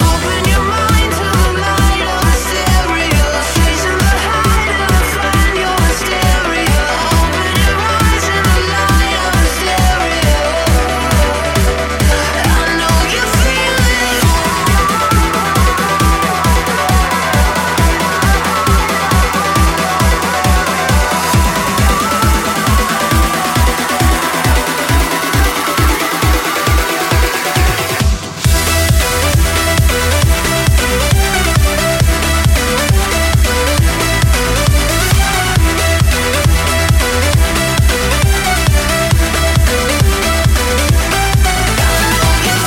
Genere: club, edm, successi, remix